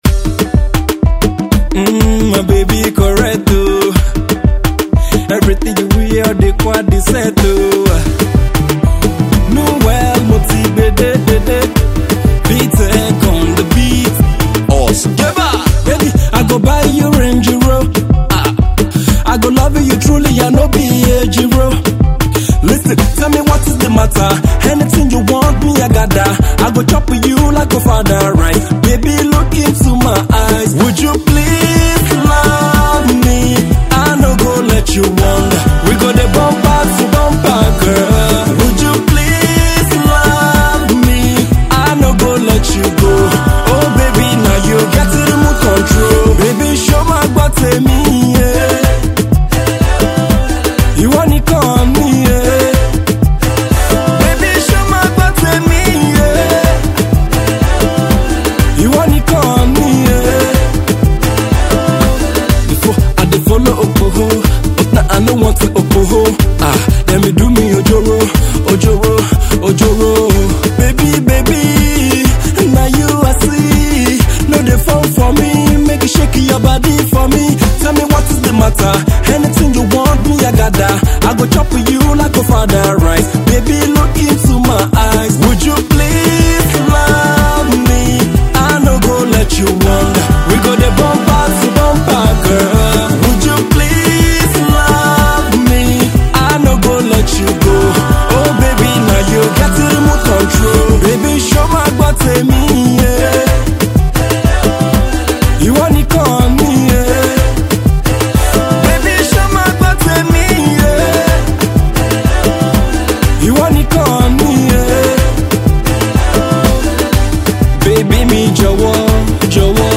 This song actually has that Woju vibe to it
Love song